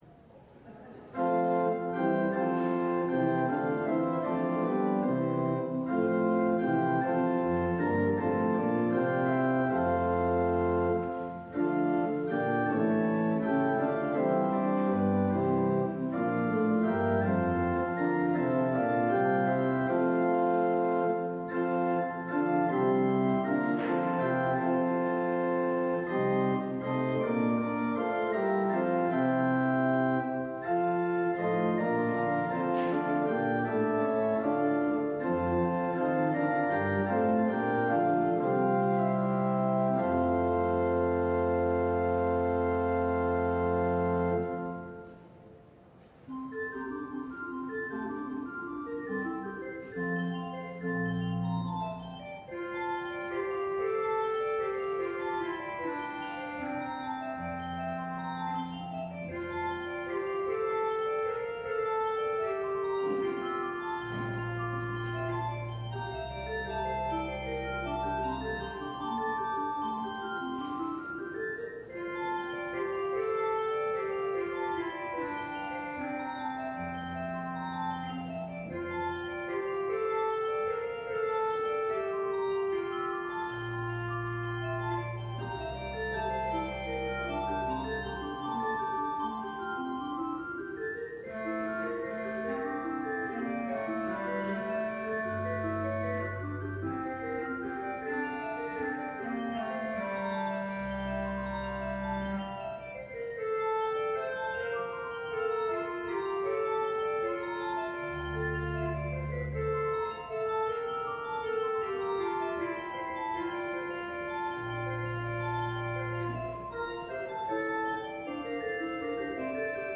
LIVE Morning Worship Service - Preparing the Way of the Lord: Ministry